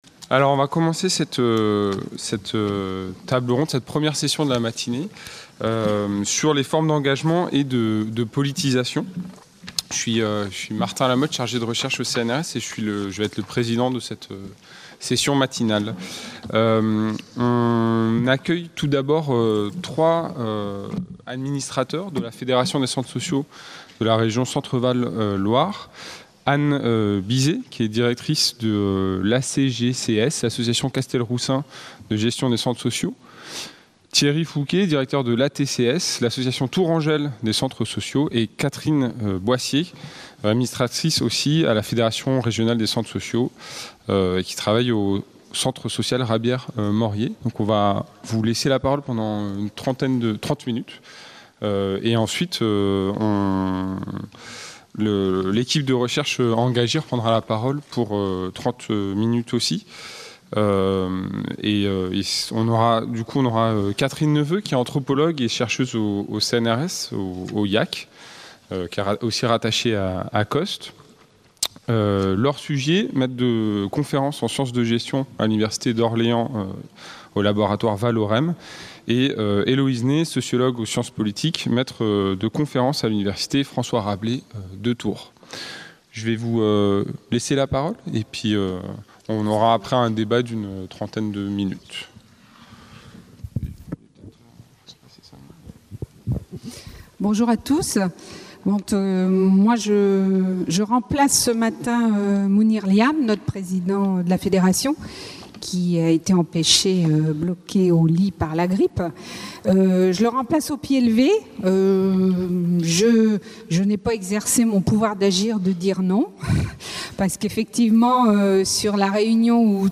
Table ronde 3